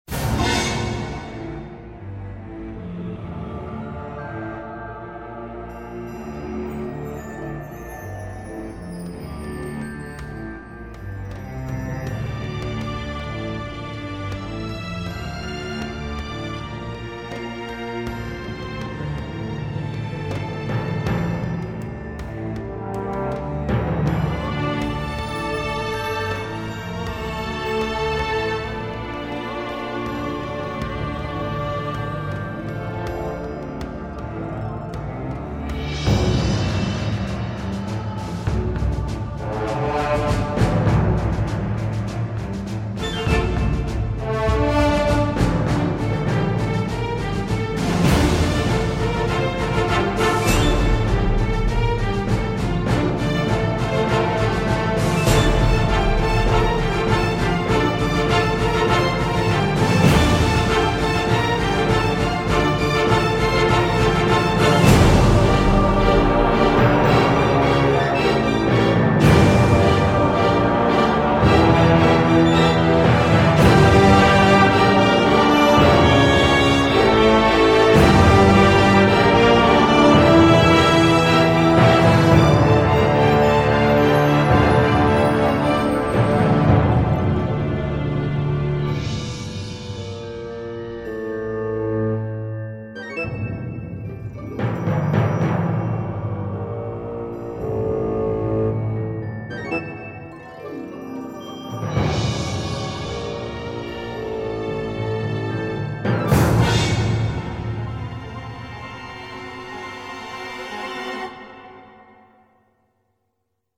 .: orchestral / movie style :.